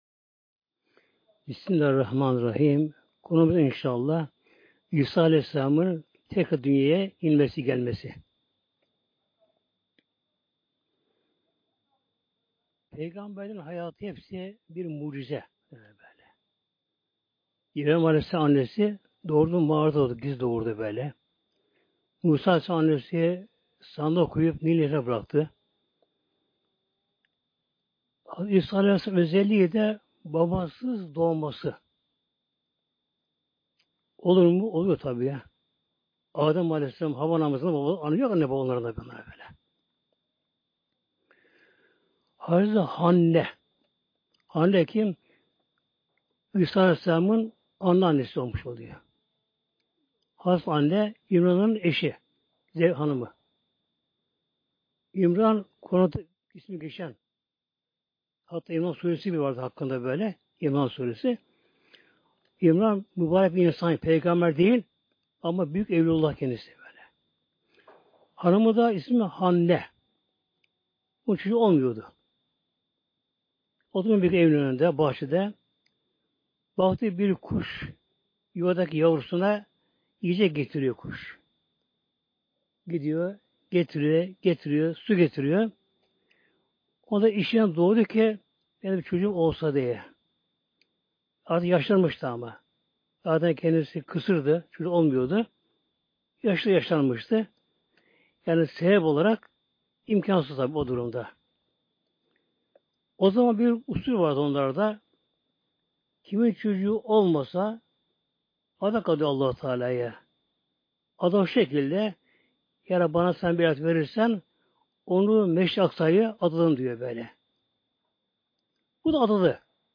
Sesli sohbeti indirmek için tıklayın (veya Sağ tıklayıp bağlantıyı farklı kaydet seçiniz)